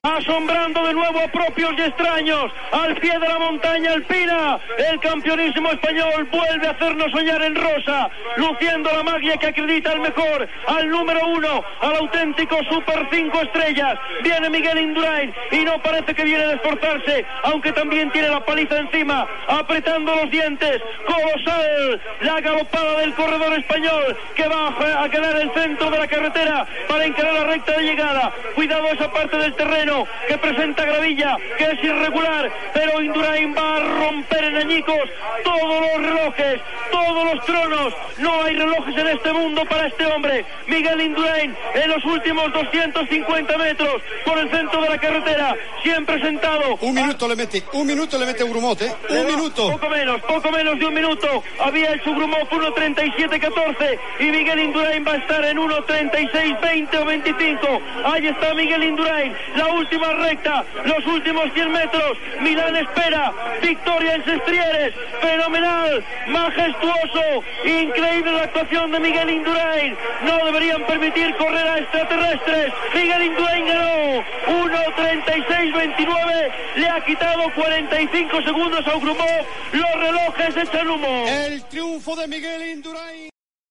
Narració de l'etapa de cronoescalada del Giro d'Itàlia Pinerolo-Sestriere.
Esportiu